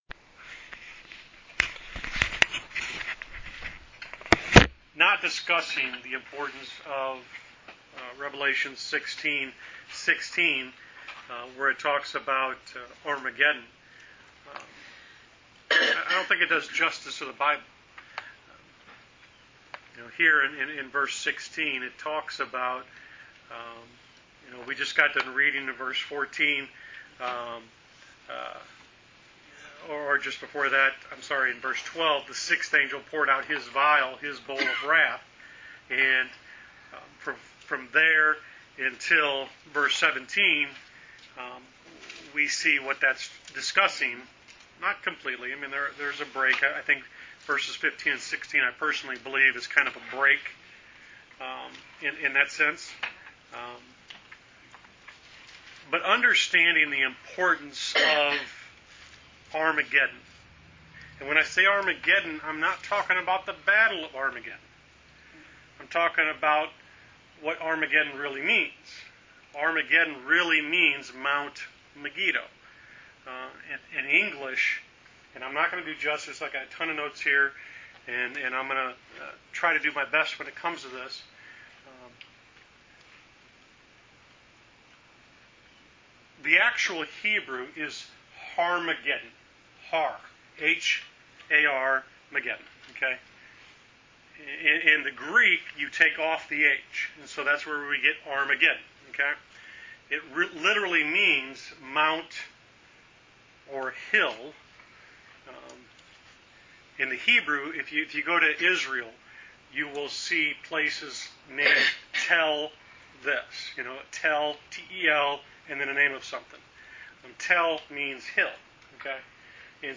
Wednesday Bible Study: Rev Ch 16 vs 16